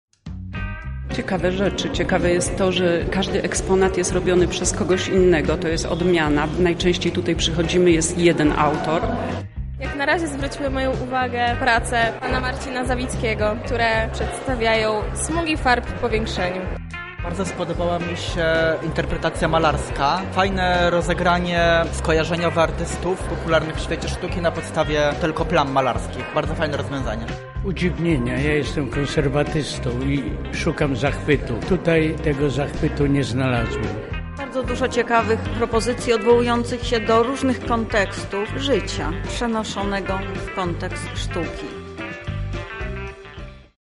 O wrażeniach rozmawialiśmy z gośćmi wernisażu.